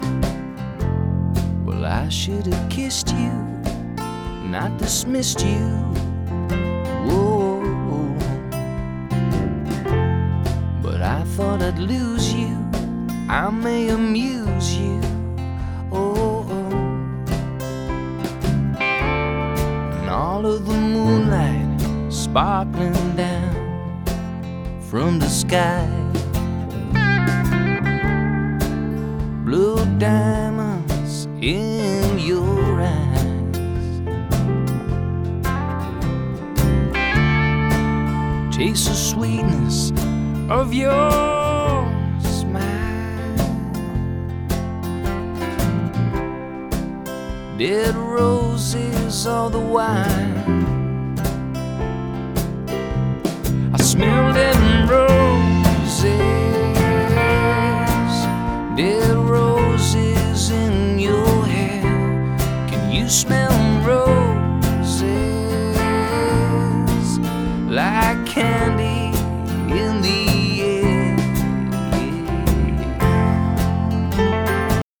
На самом громком куске вокал двигается вперёд-назад на некоторых слогах при компрессии (как будто вокалист отклоняется от микрофона).